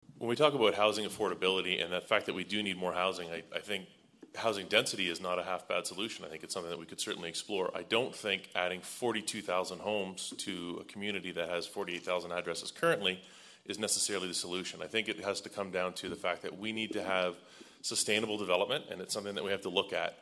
The Haldimand-Norfolk candidates met at the Royal Canadian Legion in Simcoe on Thursday night.